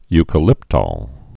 (ykə-lĭptôl, -tōl, -tŏl) also eu·ca·lyp·tole (-tōl)